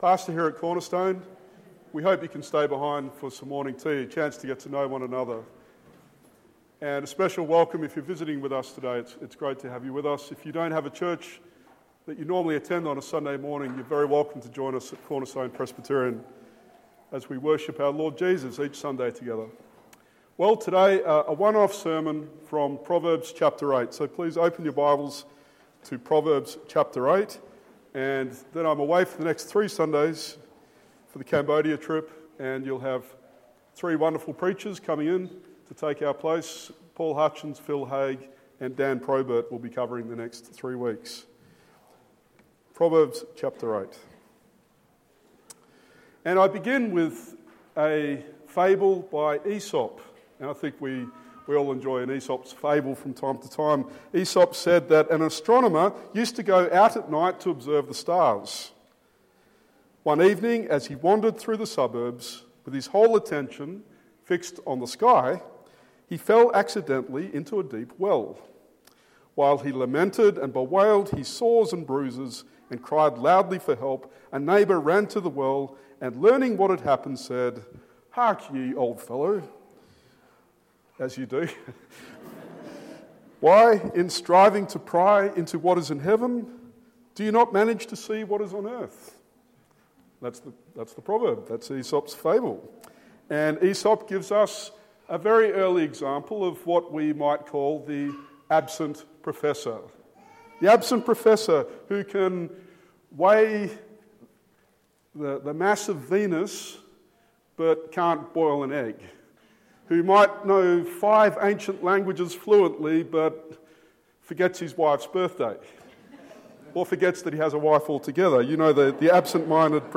Proverbs 8: Wisdom calls out to us from the street corners: \'Come and learn to be wise!\' Date Preached